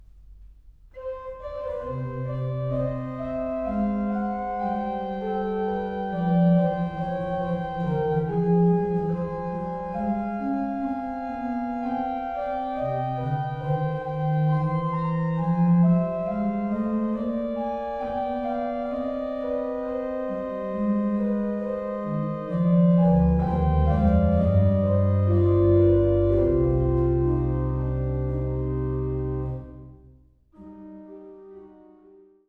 Geißler-Orgel Uebigau